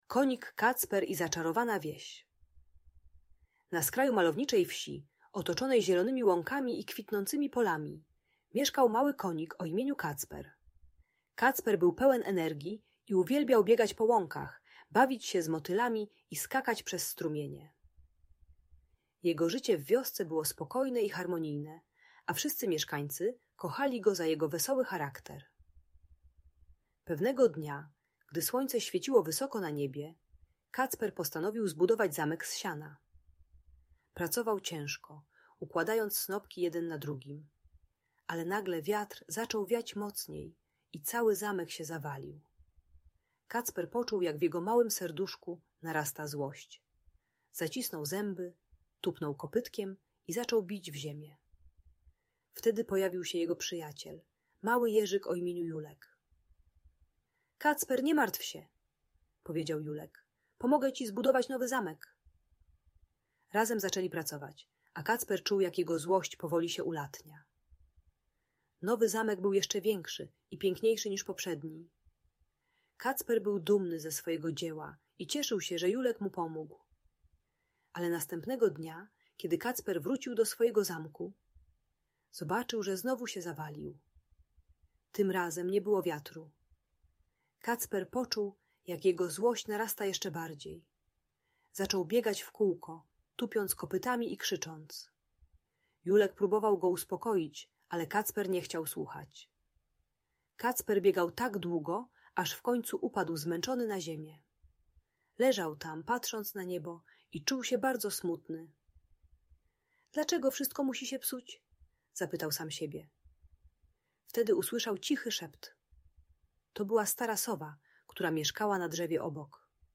Konik Kacper i Zaczarowana Wieś - Audiobajka dla dzieci